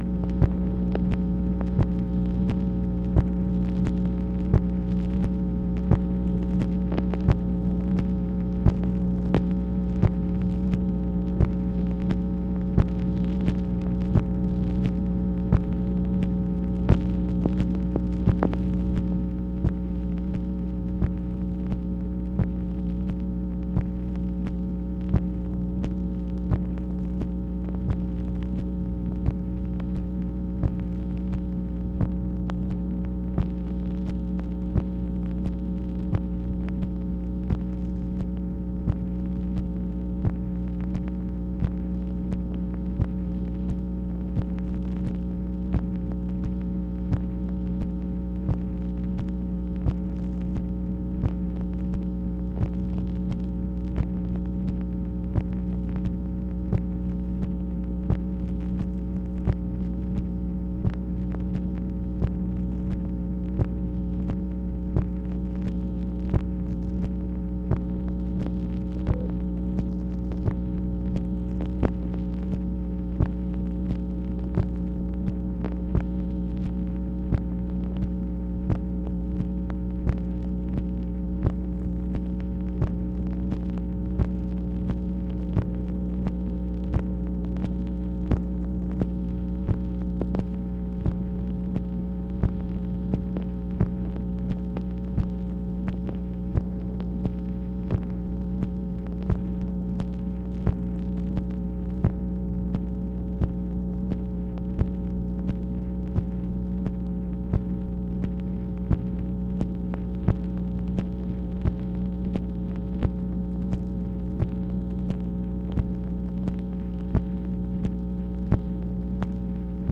MACHINE NOISE, January 23, 1967
Secret White House Tapes | Lyndon B. Johnson Presidency